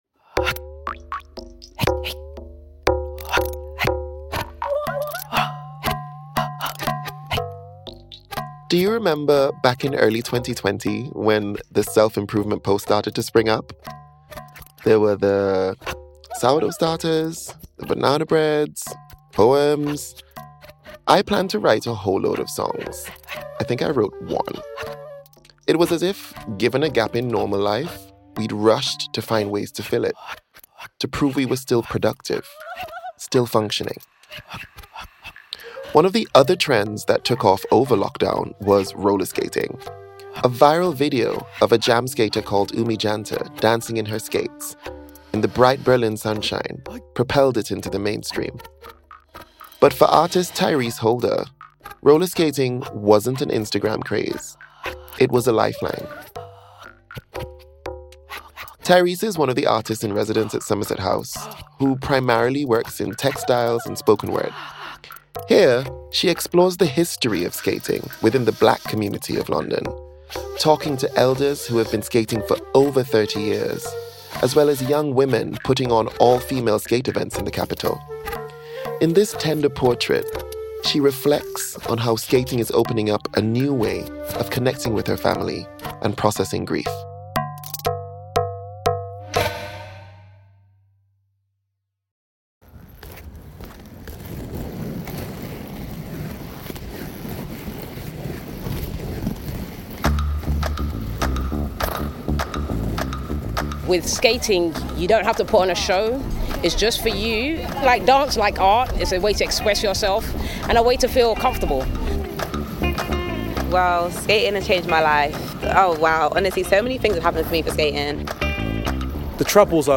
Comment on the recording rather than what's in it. She heads out to Hyde Park